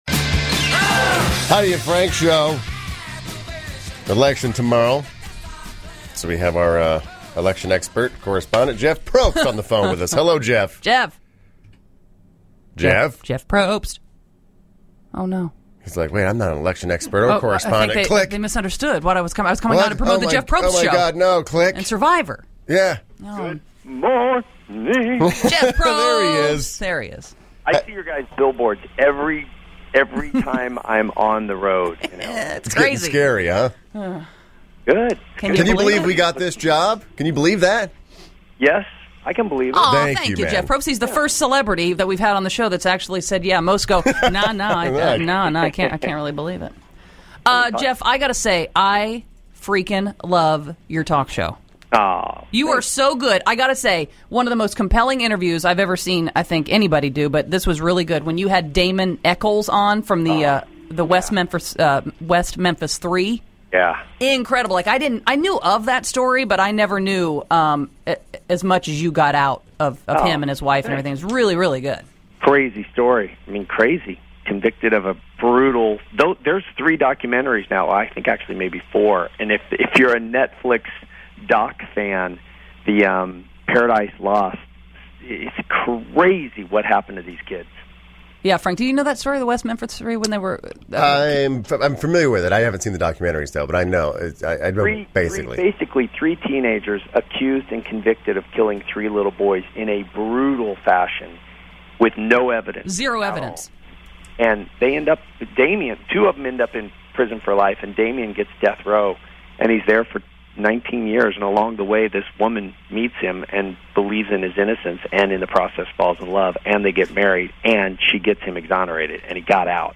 Listen November 05, 2012 - Interview - Jeff Probst - The Heidi & Frank Show